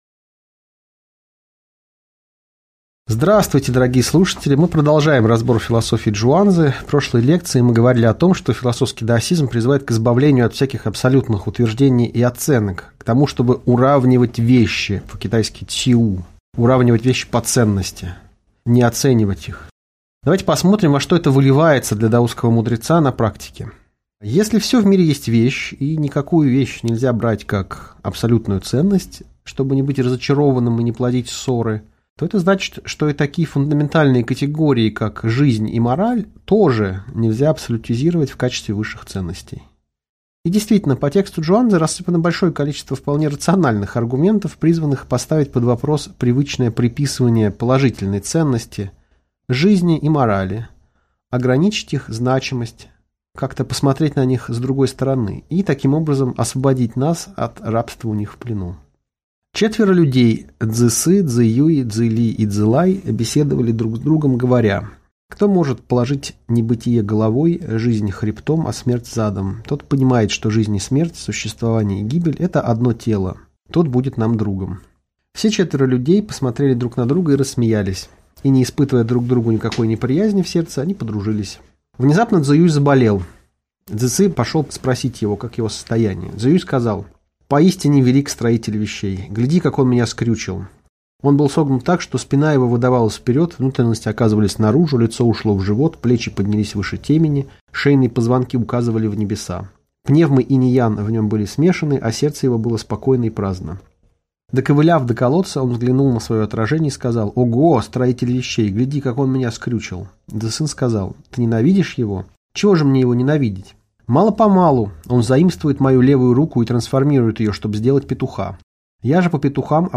Аудиокнига Лекция «Чжуан-цзы. Часть III» | Библиотека аудиокниг